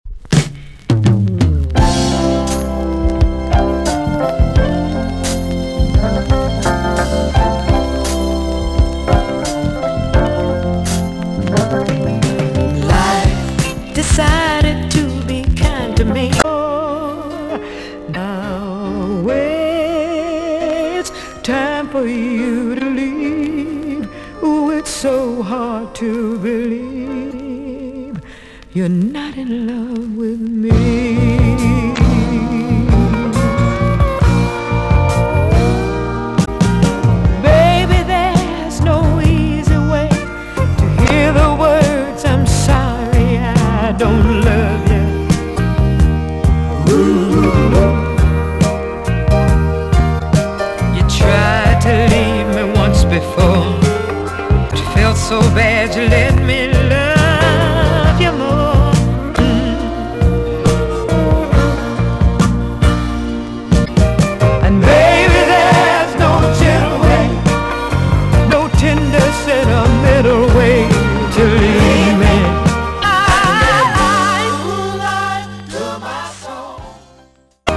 EX- 音はキレイです。
1977 , NICE JAMAICAN SOUL TUNE!!